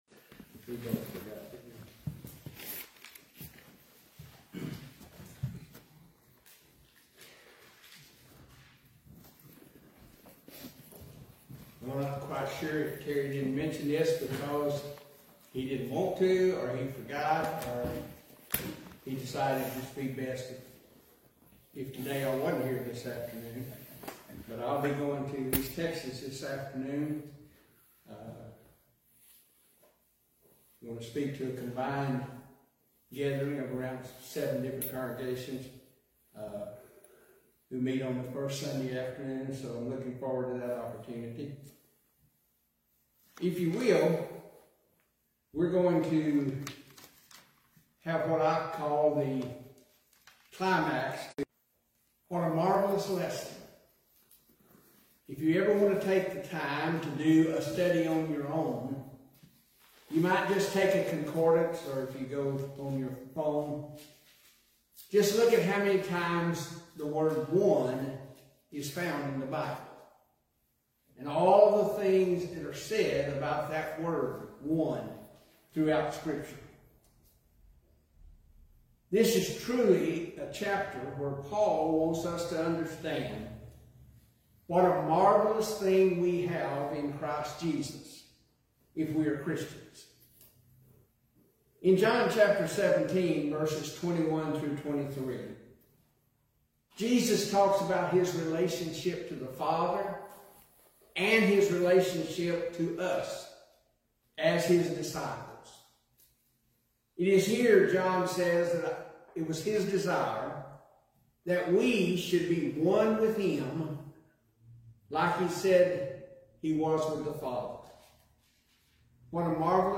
8-3-25-Sunday-AM-Sermon-Waldo.mp3